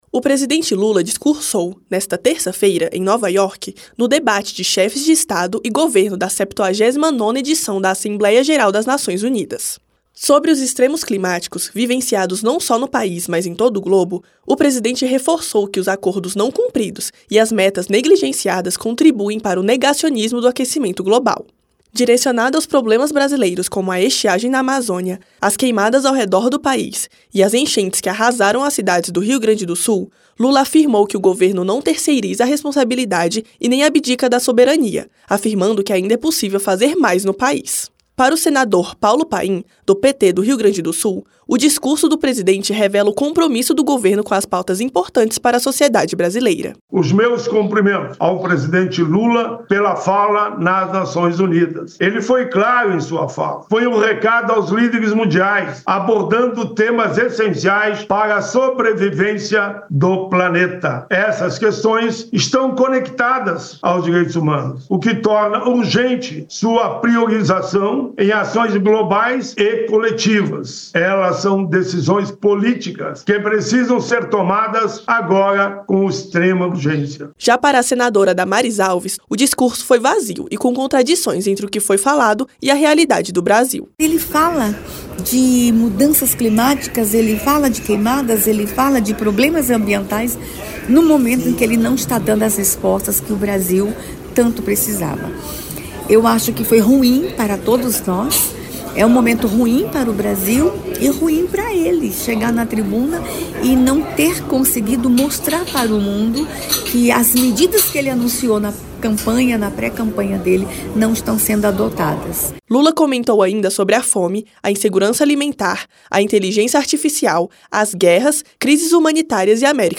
Em sua fala, a guerra, as mudanças climáticas, a inteligência artificial, a reforma tributária e a fome foram colocados como temas centrais e norteadores dos passos que a ONU pode seguir para buscar o fortalecimento coletivo entre os países. Os senadores Paulo Paim (PT-RS) e Damares Alves (Republicanos-DF) comentaram o discurso do presidente brasileiro.